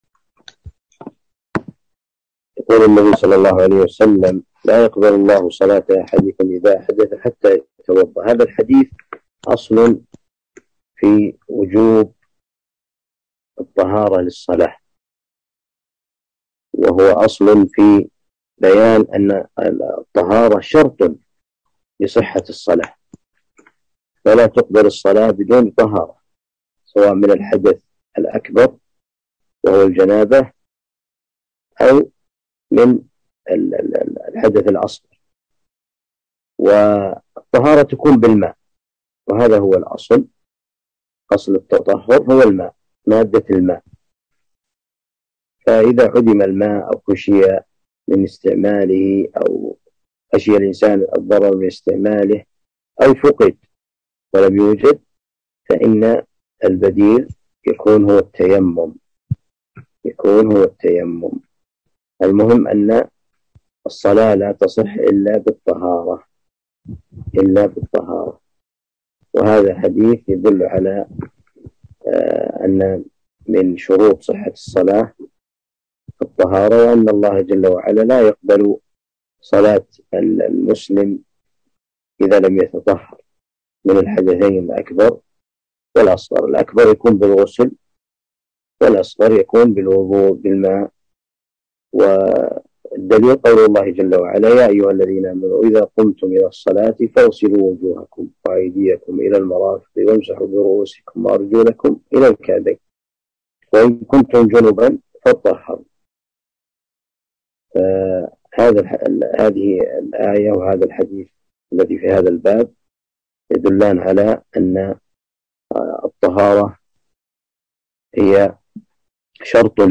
1. الدرس الأول شرح عمدة الأحكام